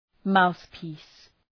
Shkrimi fonetik {‘maʋɵpi:s}
mouthpiece.mp3